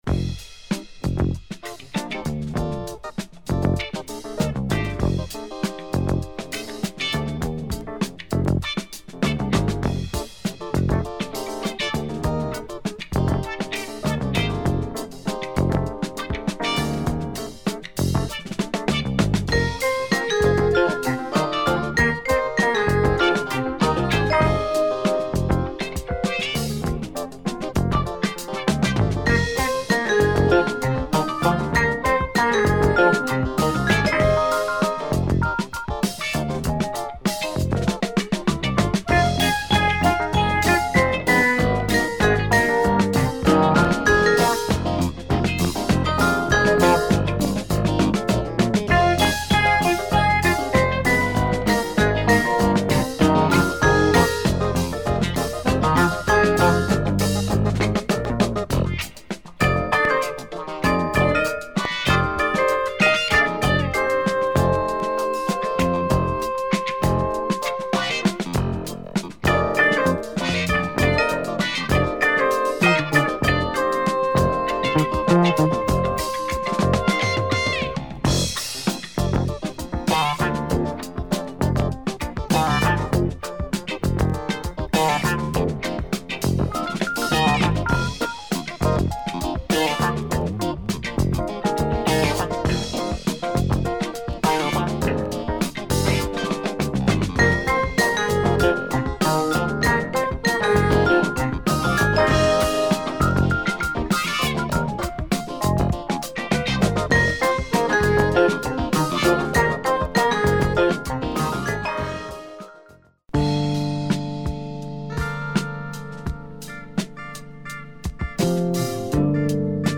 Killer funky library
soft LOOPs and sensual groove
jazz funk with breaks